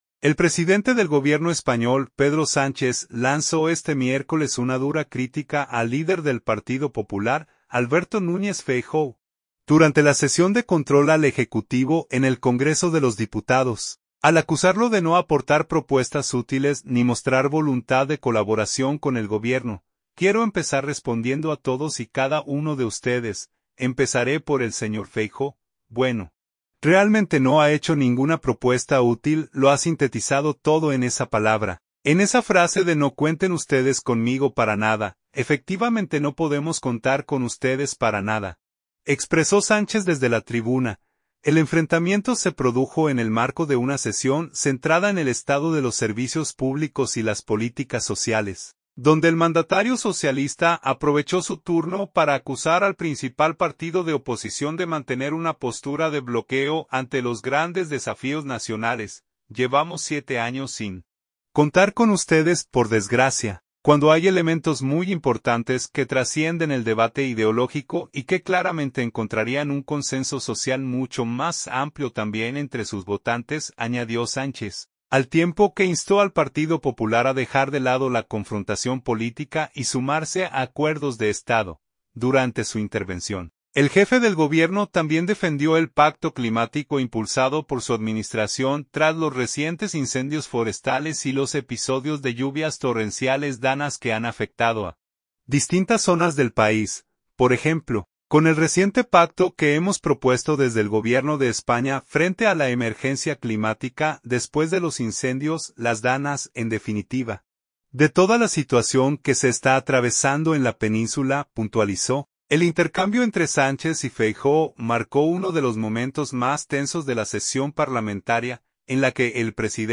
El presidente del Gobierno español, Pedro Sánchez, lanzó este miércoles una dura crítica al líder del Partido Popular, Alberto Núñez Feijóo, durante la sesión de control al Ejecutivo en el Congreso de los Diputados, al acusarlo de no aportar propuestas útiles ni mostrar voluntad de colaboración con el Gobierno.